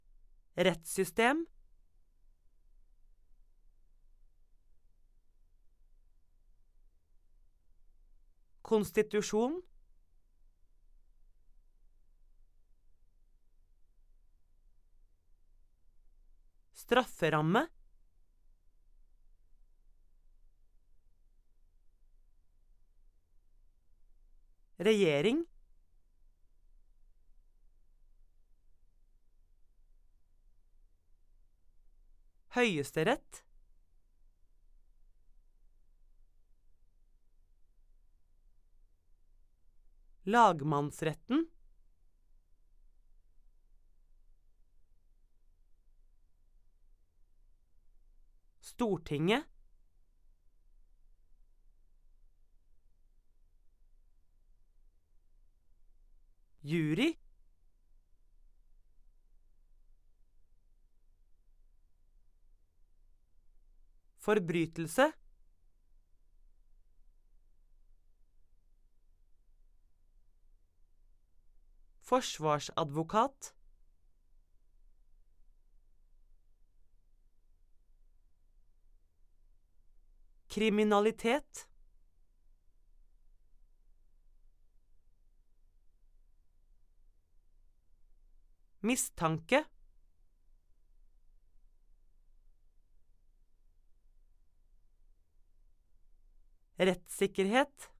8.2.1 Uttale (ord)
Lytt og gjenta.